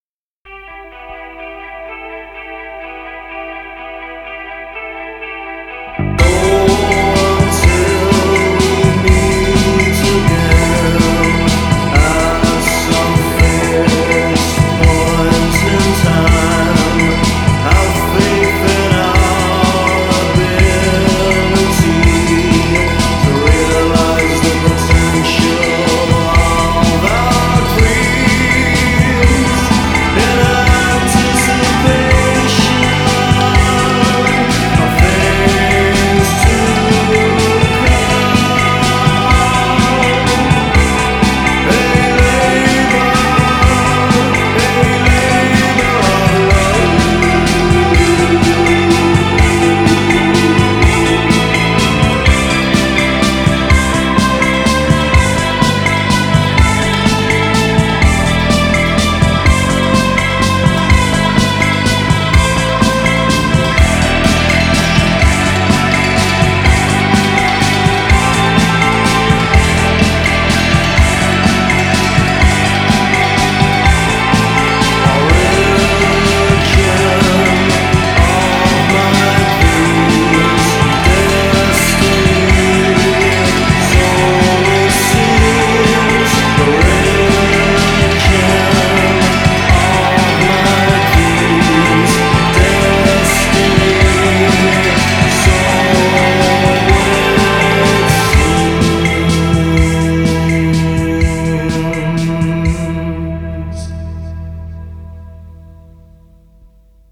BPM125
Audio QualityPerfect (High Quality)
with a goth rock/post punk sound